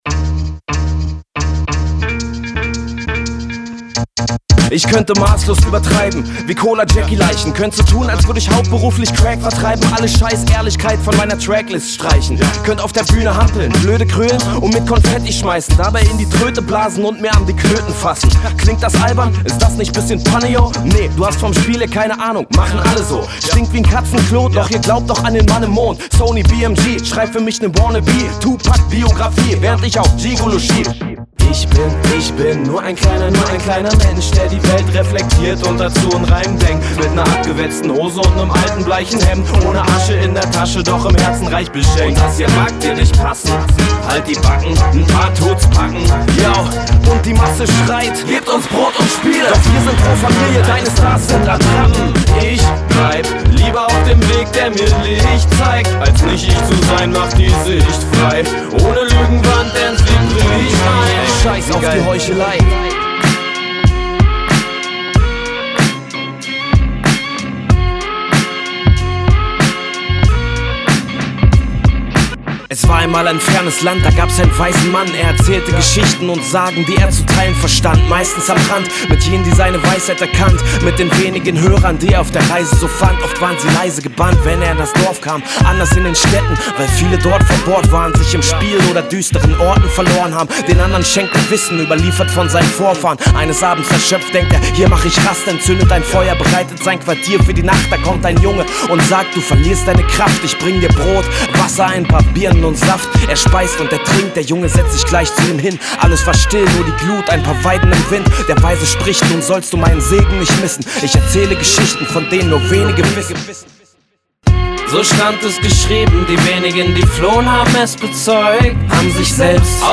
HipHop
Rapper und Produzent
Die gerappt / gesungenen Lieder
soulig-herzhaften Sound